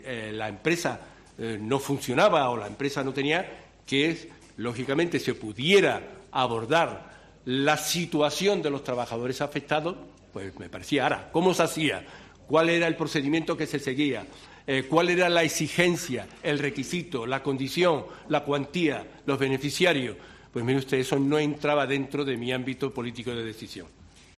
El expresidente andaluz Manuel Chaves empieza a declarar en juicio de los ERE